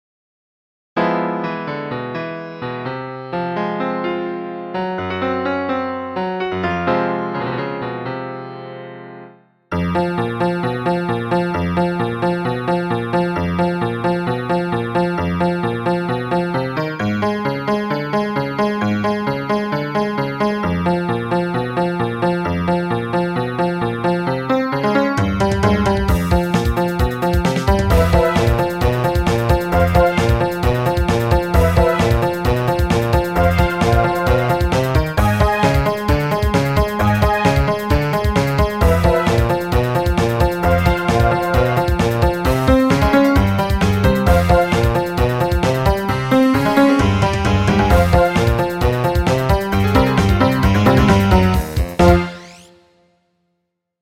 STRANGE MUSIC ; ROCK MUSIC